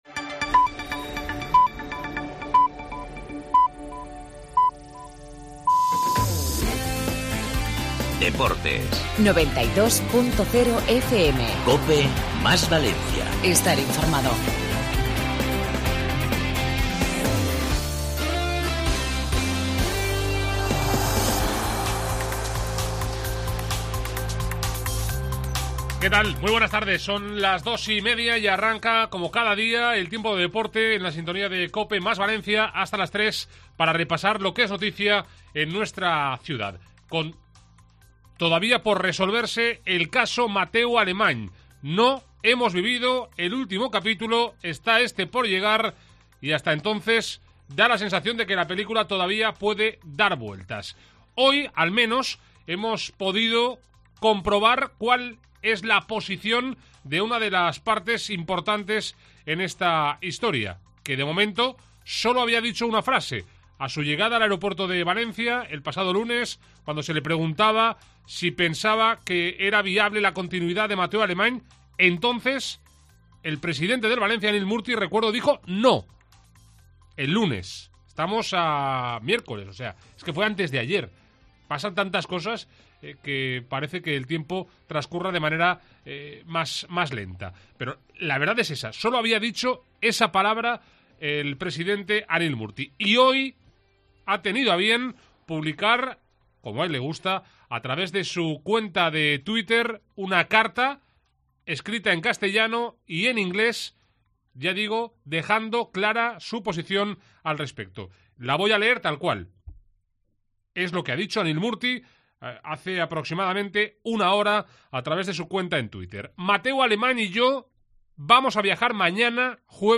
El Levante, a por Óscar Duarte. Entrevistamos a Borja Mayoral.